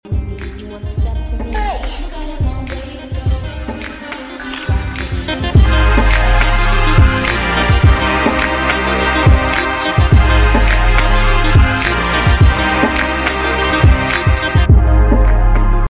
smile voiceover & camera flash edit audio